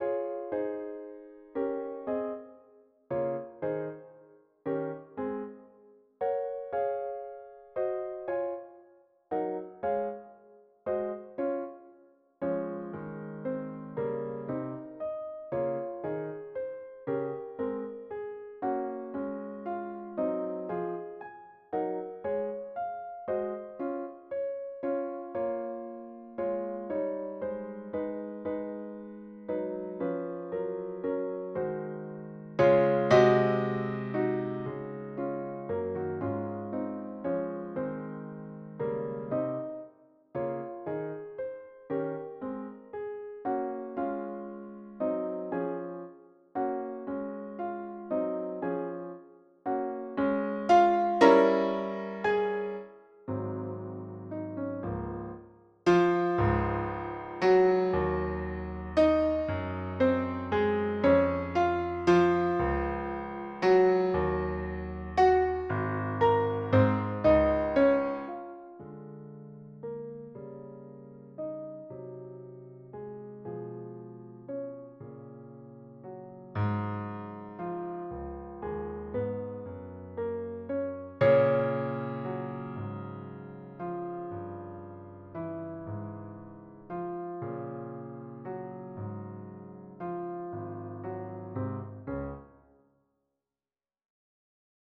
Key: C-sharp minor)